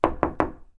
房子 " 敲木门2